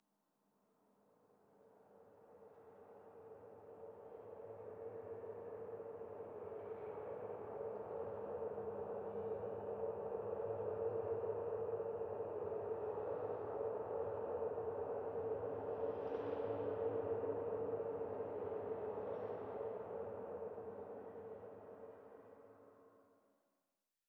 06_公寓楼道.wav